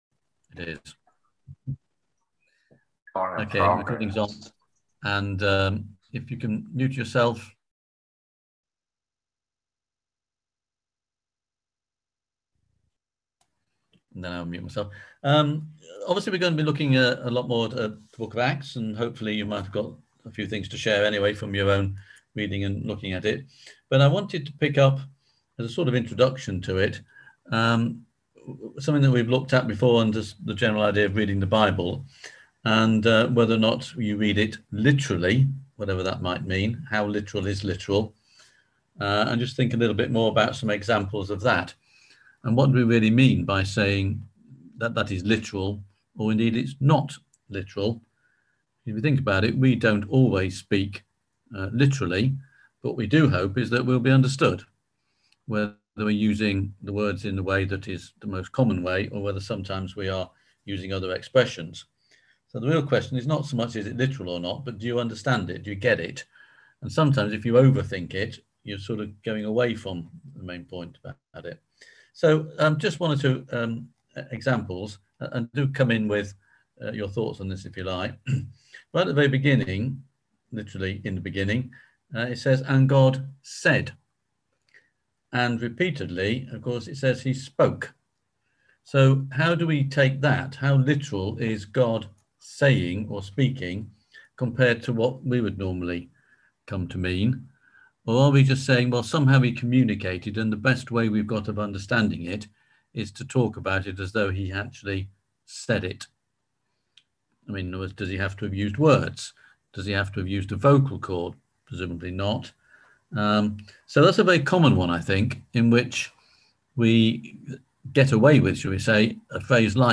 On October 7th at 7pm – 8:30pm on ZOOM